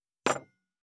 234,机に物を置く,テーブル等に物を置く,食器,グラス,コップ,工具,小物,雑貨,コトン,トン,ゴト,ポン,ガシャン,